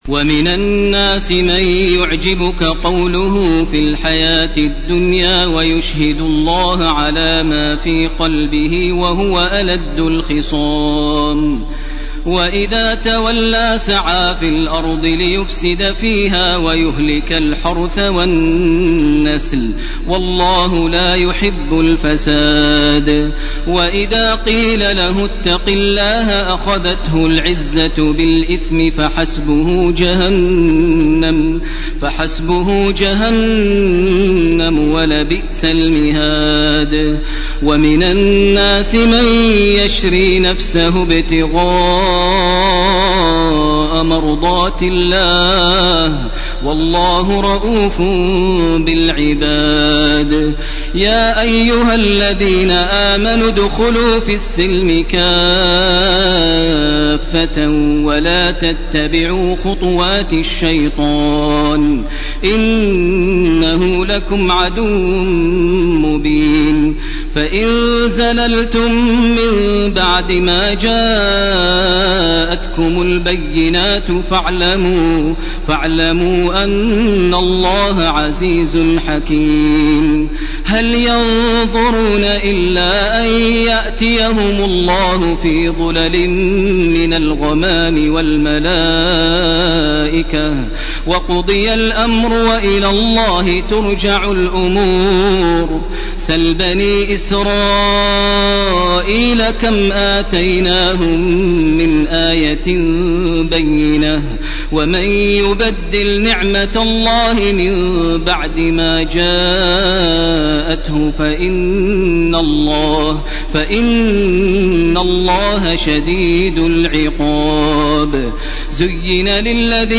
ليلة 02 رمضان لعام 1431 هـ من الآية 204 من سورة البقرة وحتى الآية 252 من سورة البقرة. > تراويح ١٤٣١ > التراويح - تلاوات ماهر المعيقلي